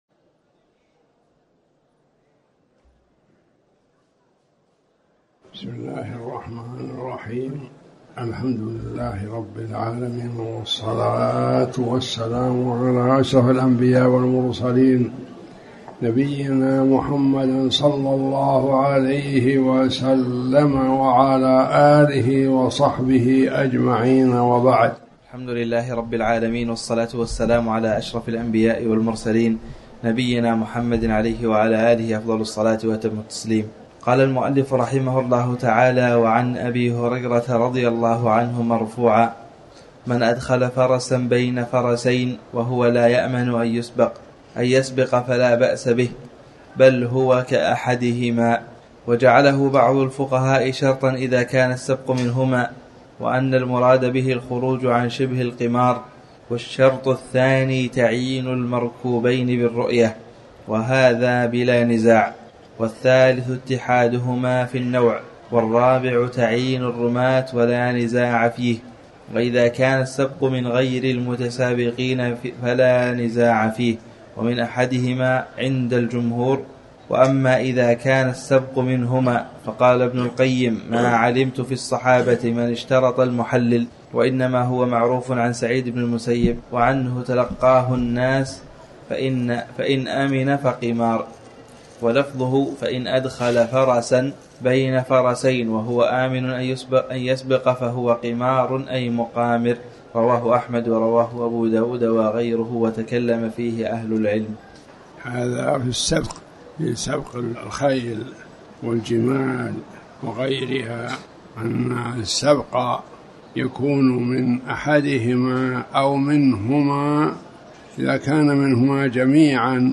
تاريخ النشر ٤ جمادى الآخرة ١٤٤٠ هـ المكان: المسجد الحرام الشيخ